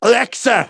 synthetic-wakewords
ovos-tts-plugin-deepponies_Demoman_en.wav